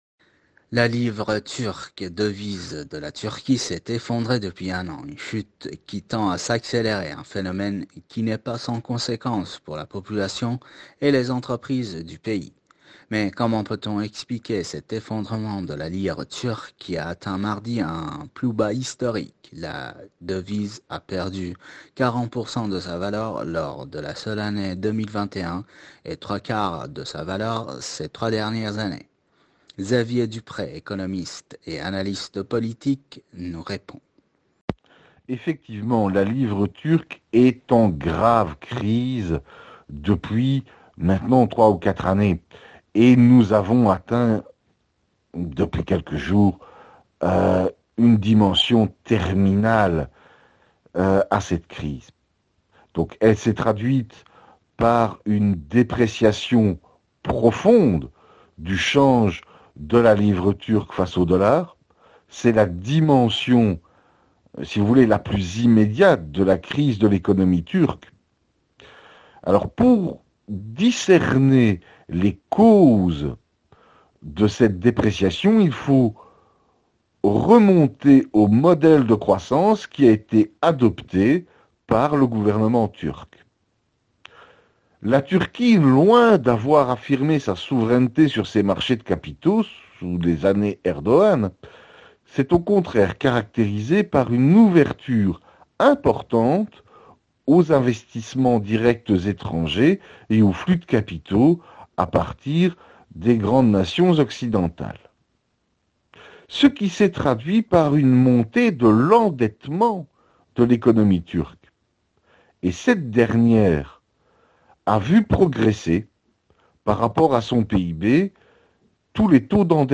Mots clés Turquie interview économie Eléments connexes Le groupe MAPNA : le cœur battant de l’industrie iranienne La Turquie et Israël : vers un conflit armé ?